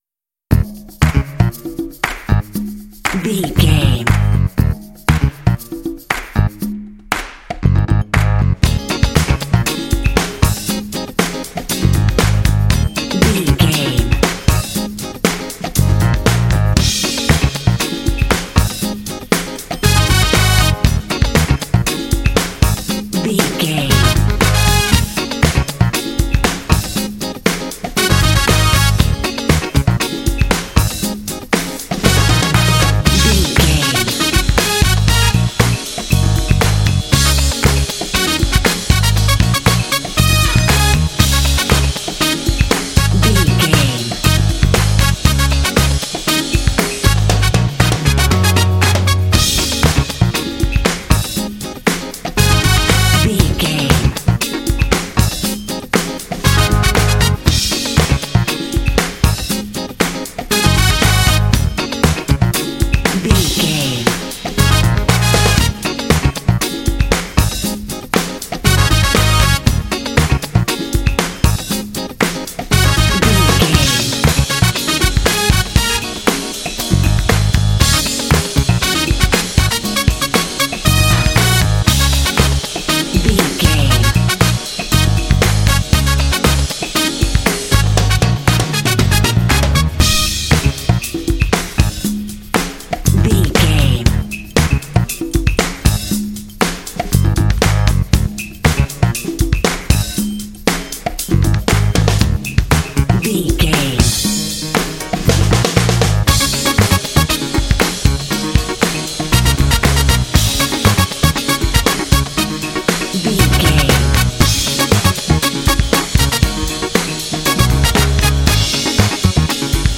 Epic / Action
Aeolian/Minor
E♭
groovy
energetic
driving
bouncy
saxophone
bass guitar
electric guitar
drums
brass